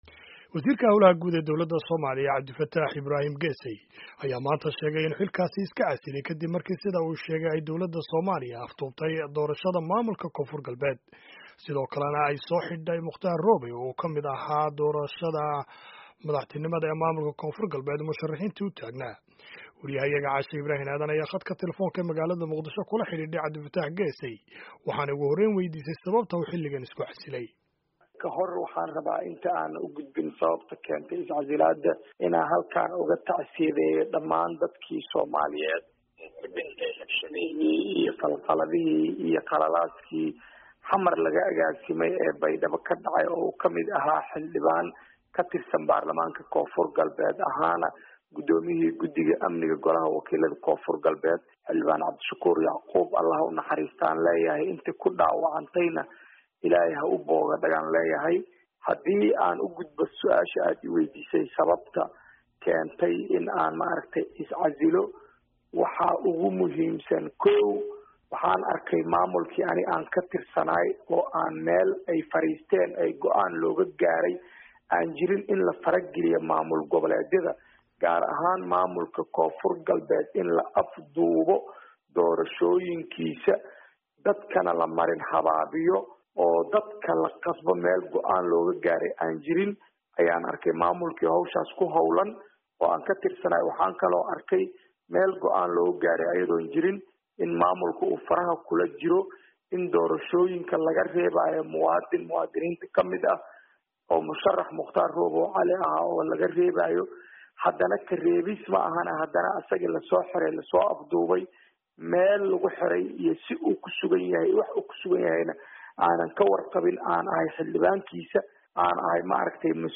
Wareysi: Wasiir Cabdifataax Geesey
ayaa khadka telefoonka ee Muqdisho ku wareysatay Wasiir Geeseey